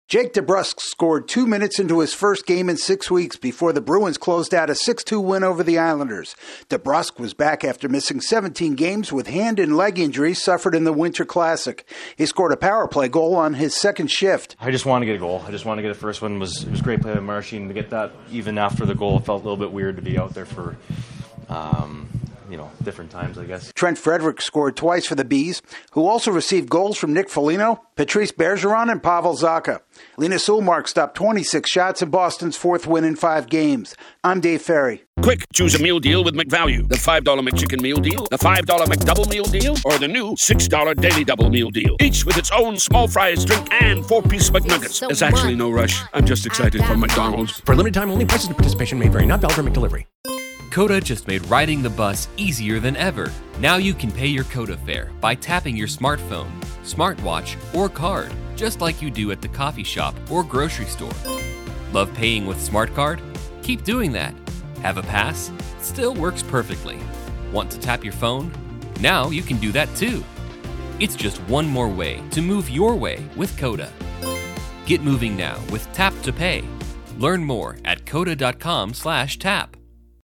A returning player jumpstarts the Bruins' romp over the Islanders. AP correspondent